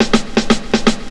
FILL 2    -L.wav